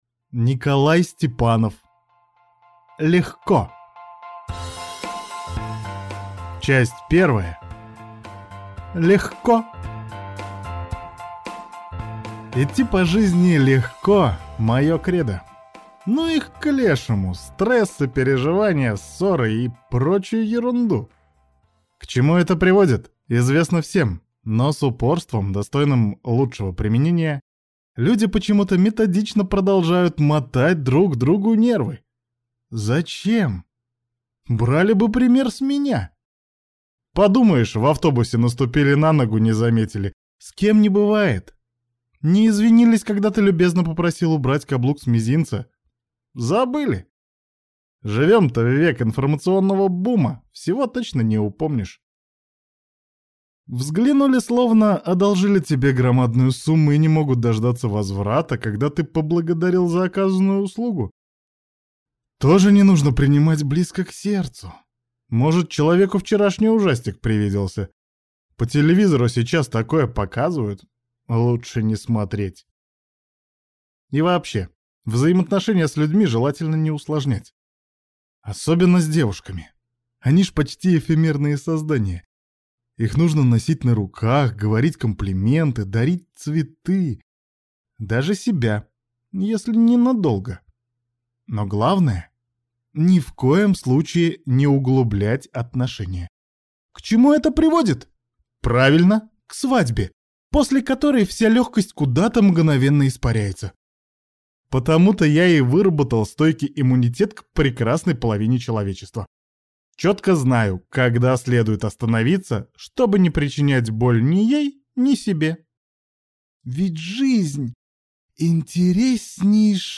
Аудиокнига Легко!